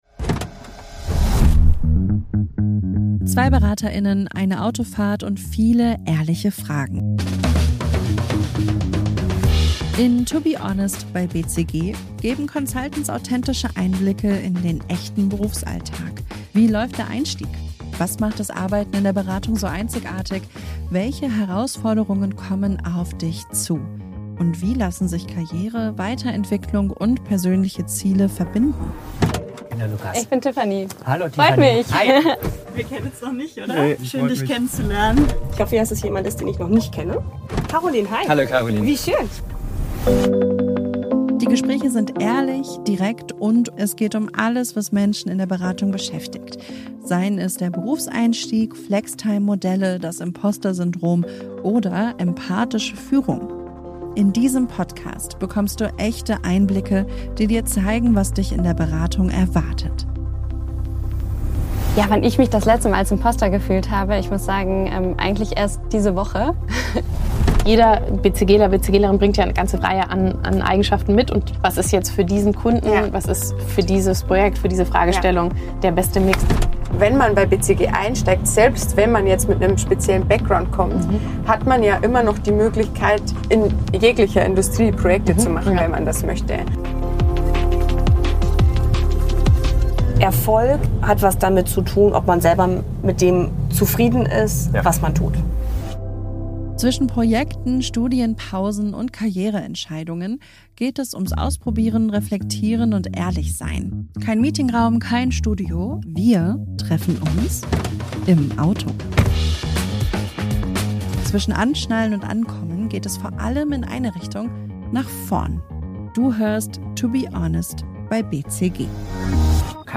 Zwei Berater:innen, eine Autofahrt und viele ehrliche Fragen.
Ohne Skript, ohne Floskeln, dafür mit echten